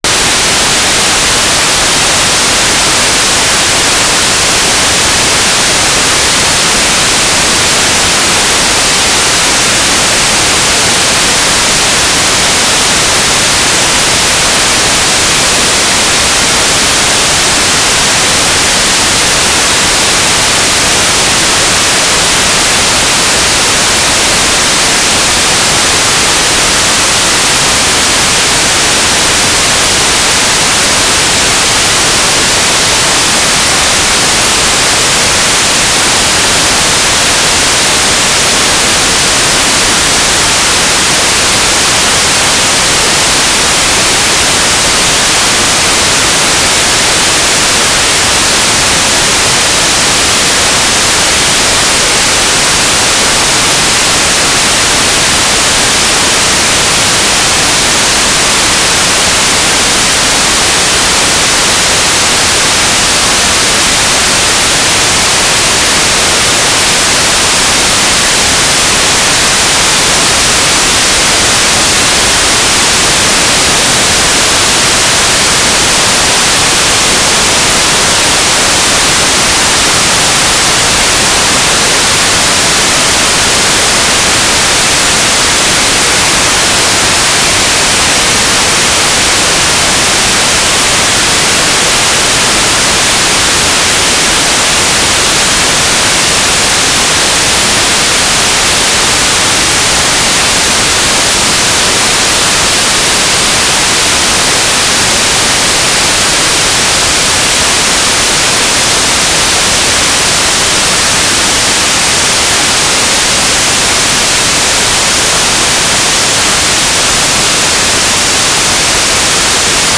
"waterfall_status": "without-signal",
"transmitter_description": "S-band telemetry",
"transmitter_mode": "FM",